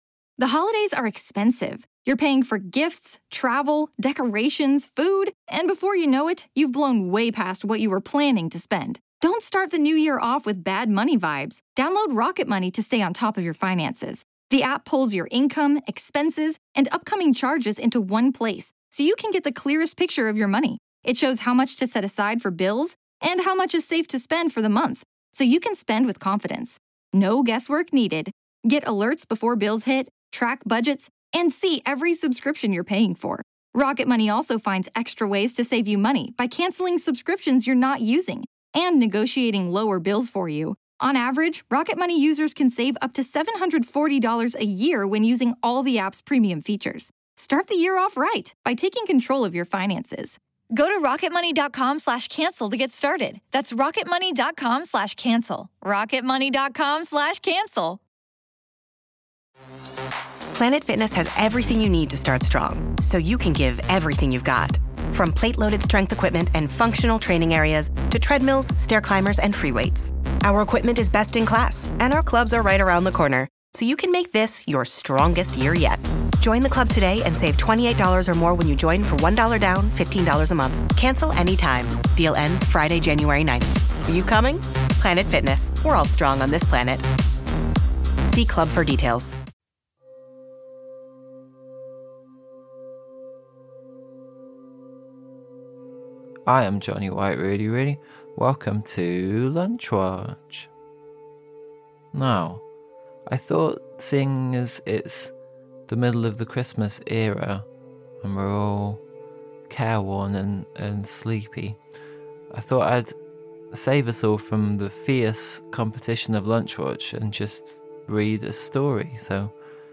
short story